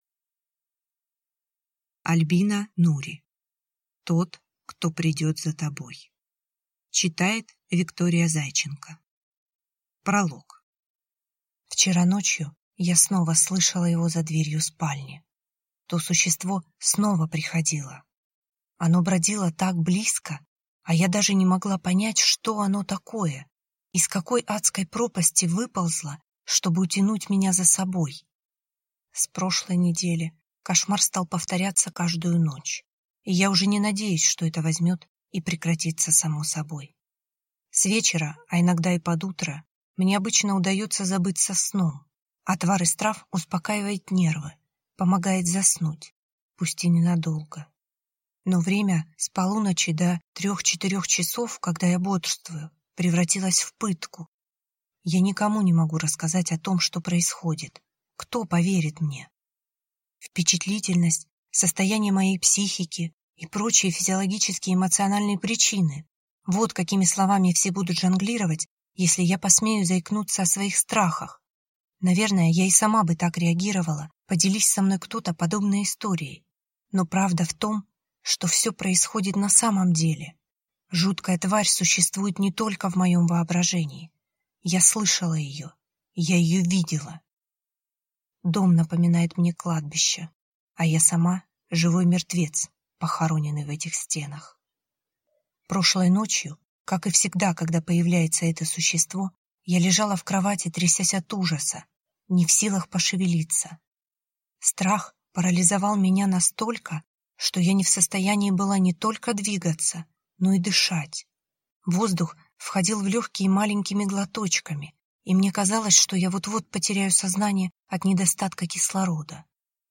Аудиокнига Тот, кто придет за тобой | Библиотека аудиокниг